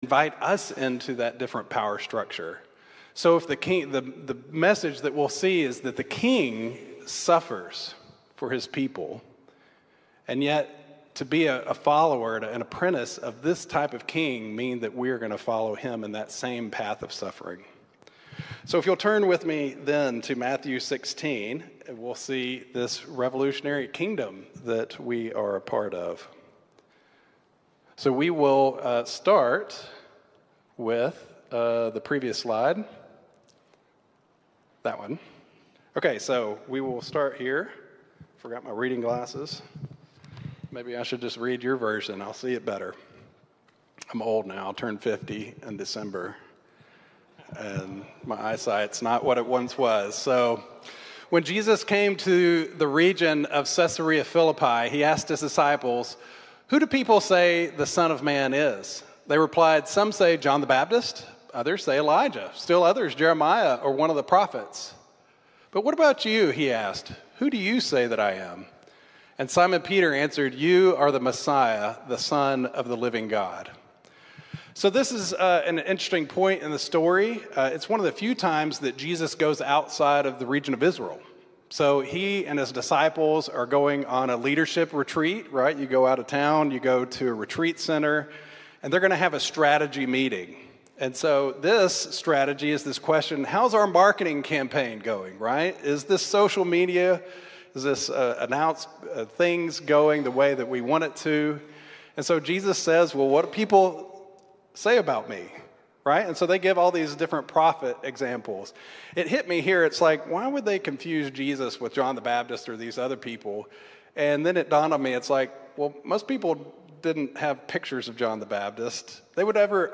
Weekly Sunday messages from Gloucester Vineyard Church.